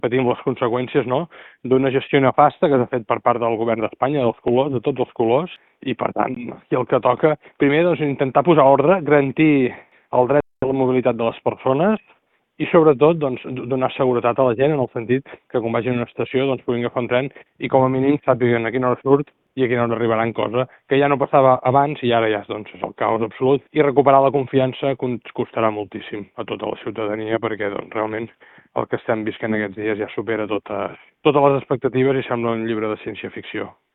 En l’àmbit local, l’alcalde de Calella, Marc Buch, ha denunciat l’històric de desinversió a la xarxa ferroviària, que ha atribuït a una gestió que ha qualificat de “nefasta” per part de l’Estat. En declaracions a Ràdio Calella TV, ha reclamat que es posi ordre al servei de Rodalies i es garanteixi el dret a la mobilitat de la ciutadania.